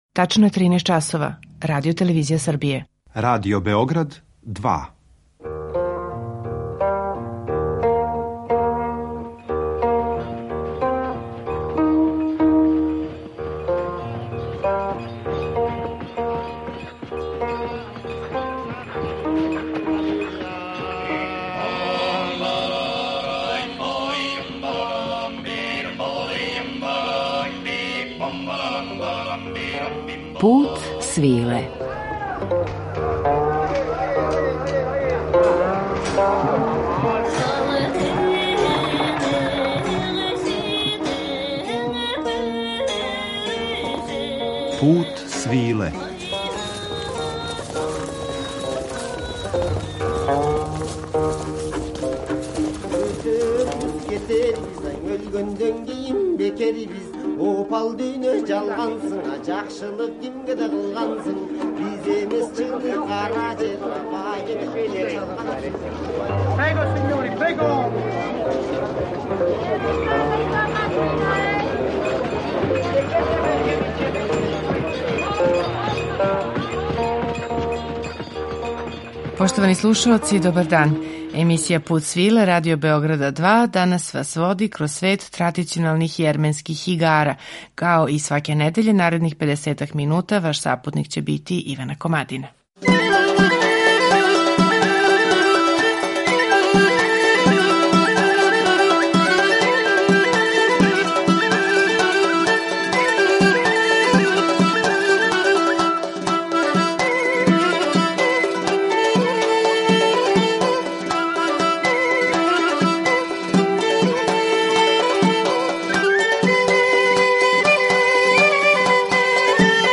У данашњем Путу свиле откривамо свет традиционалних јерменских игара. У интерпретацији ансамбла „Шогакен" слушаћете музику за плесове уз жетву, надметања младића, ратничке плесове, свадбарске игре...